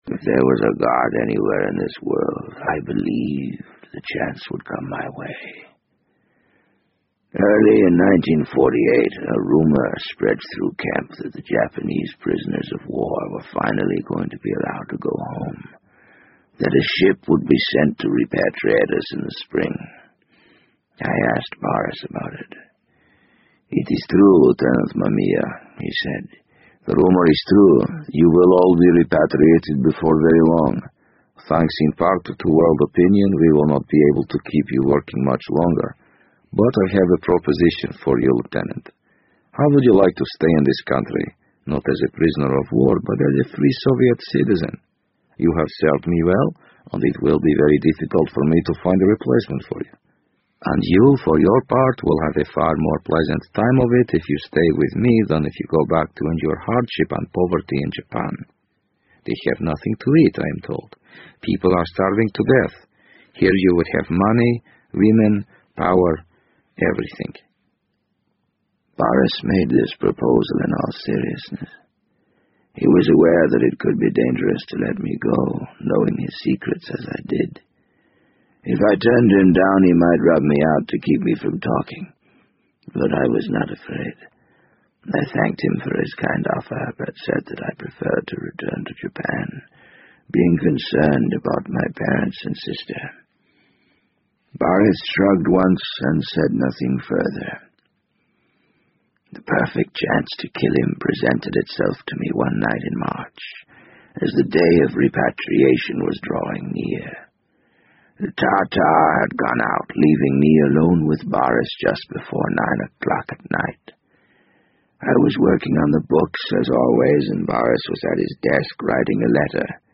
BBC英文广播剧在线听 The Wind Up Bird 014 - 17 听力文件下载—在线英语听力室